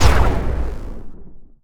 poly_explosion_nuke3.wav